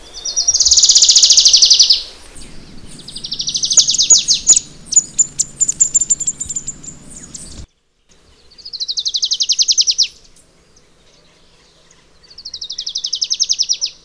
"Adelaide's Warbler"
reinita-mariposera.wav